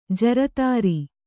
♪ jaratāri